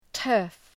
Προφορά
{tɜ:rf}